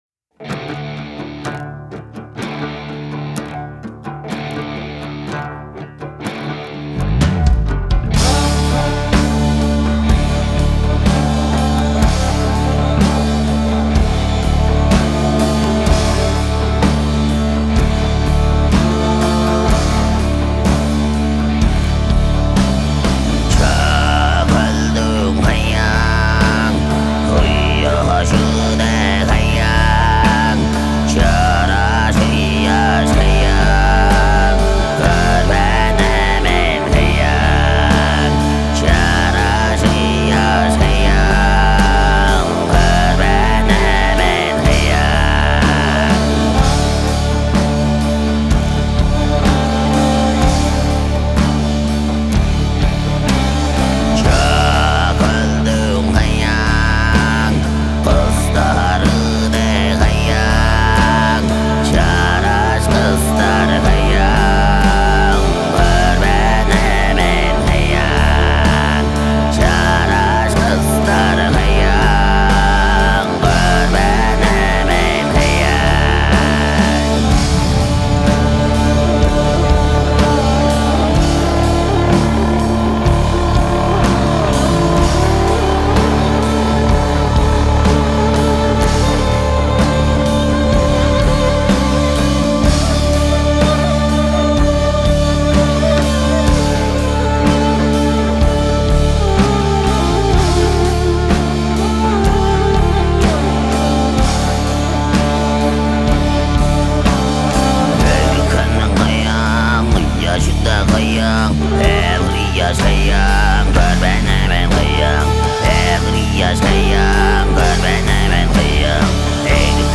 Psychodelic ethno-rock band